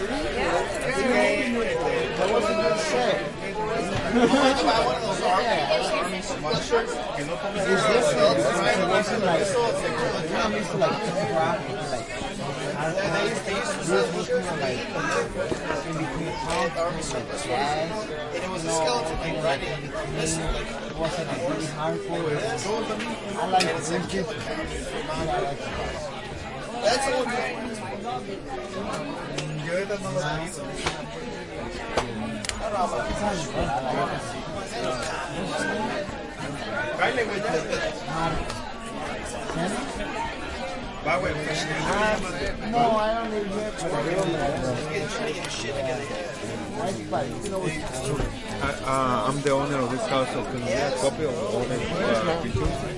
蒙特利尔 " 人群中的画廊Belgo大楼年轻的时髦人士人群木地板的人进入2更多魁北克蒙特利尔，加拿大
描述：人群int媒体画廊贝尔戈建设年轻时髦人群木地板人进入2更多魁北克蒙特利尔，Canada.flac
Tag: 蒙特利尔 画廊 进入 时髦的 木材 贝尔戈 年轻 INT 地板 人群 中等 加拿大 建筑